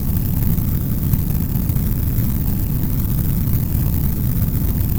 thrusterFire_001.ogg